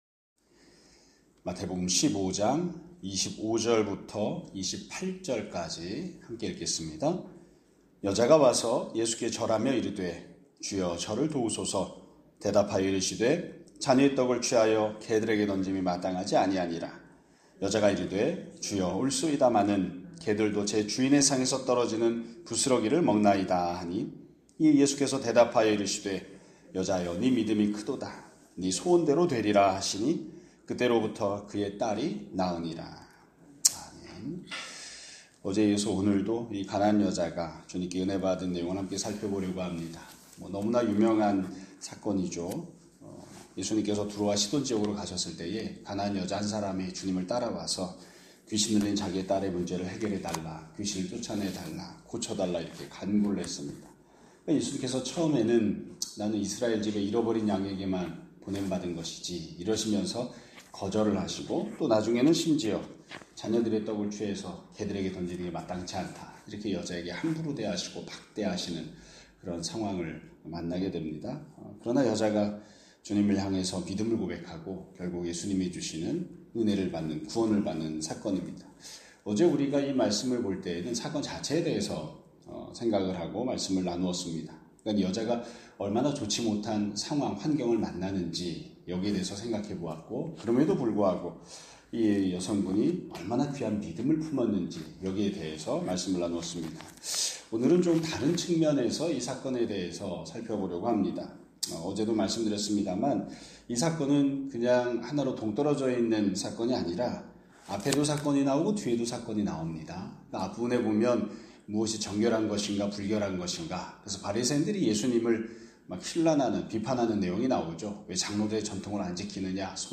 2025년 11월 7일 (금요일) <아침예배> 설교입니다.